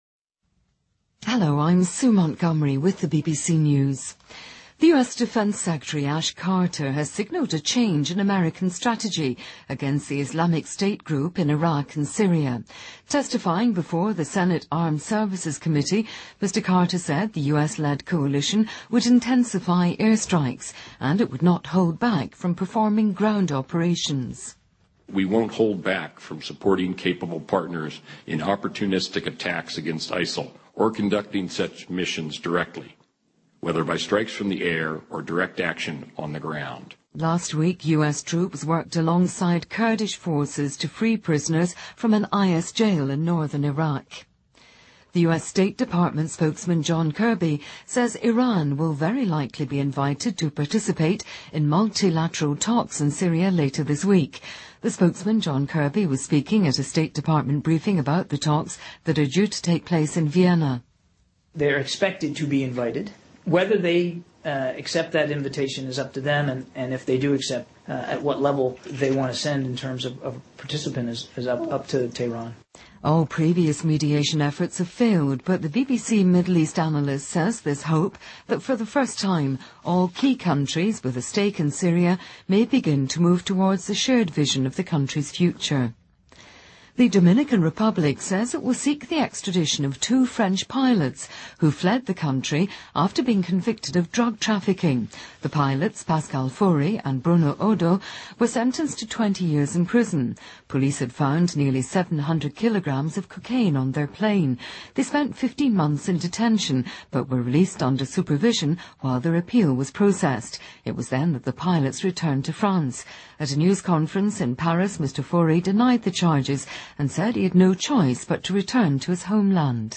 BBC news,伊朗首次参加叙利亚和谈